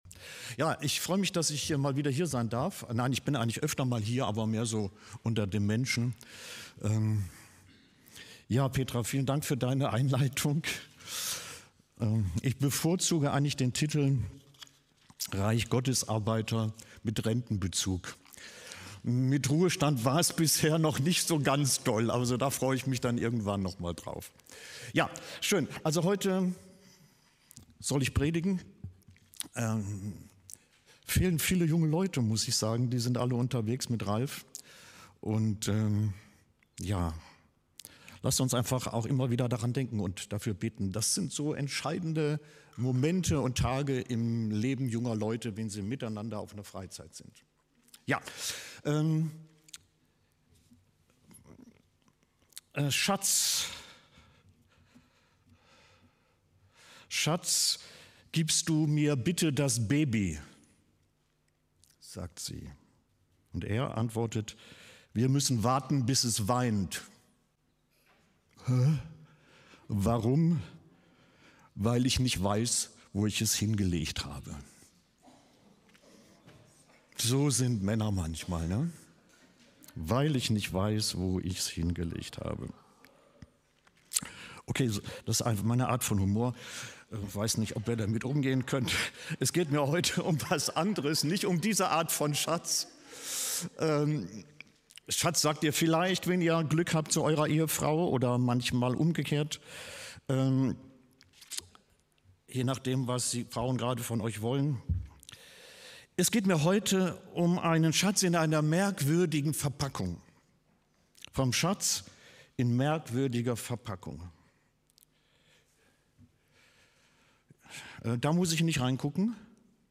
FeG Aschaffenburg - Predigt Podcast
FeG_Gottesdienst_am_25-05.mp3